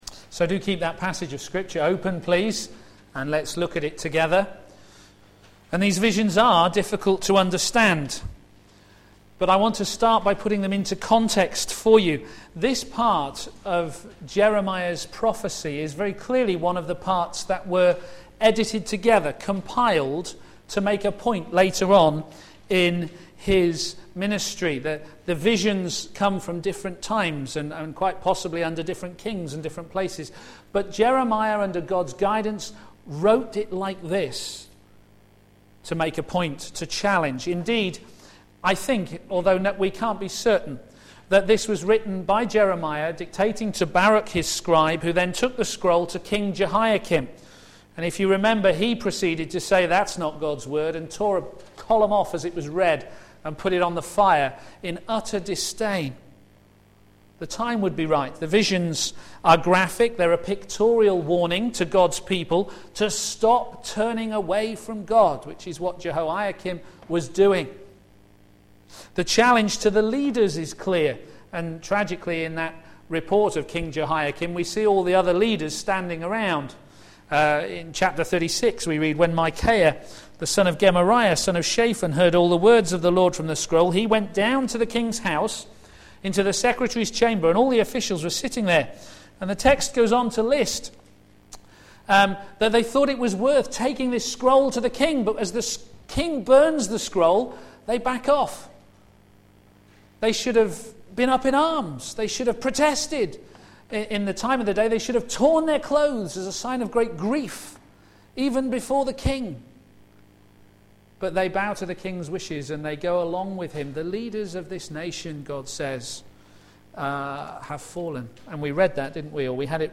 Media for a.m. Service on Sun 04th Sep 2011 10:30